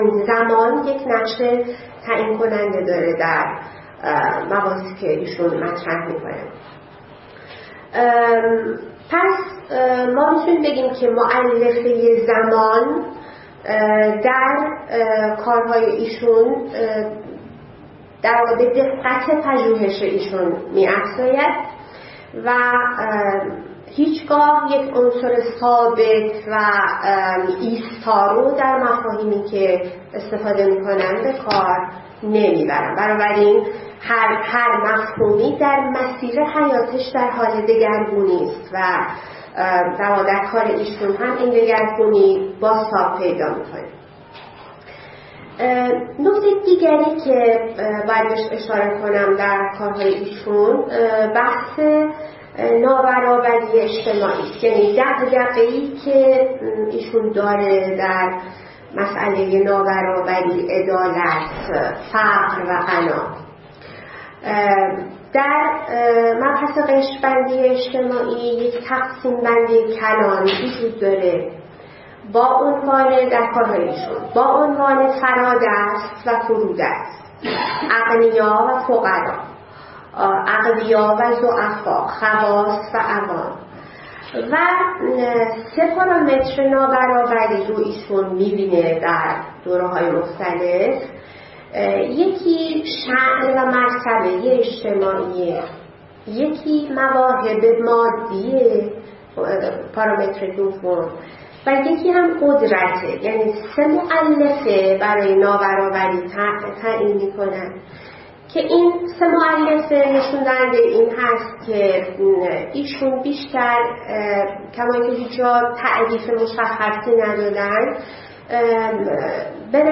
فرهنگ امروز: نخستین نشست از سلسله نشست‌های تاریخ نظریه ای/مفهومی و جامعه شناسی تاریخی ایران با موضع بررسی انتقادی کارنامه علمی احمد اشرف به همت فصلنامه مردم نامه و با همکاری و همراهی انجمن ایرانی تاریخ، انجمن جامعه شناسی ایران، خانه اندیشمندان علوم انسانی، انجمن علوم سیاسی ایران، دانشکده علوم اجتماعی دانشگاه تهران وموسسه نگارستان اندیشه در روز ۱۵ اردیبهشت ماه۱۳۹۷در سالن علی شریعتی دانشکده علوم اجتماعی دانشگاه تهران برگزار شد.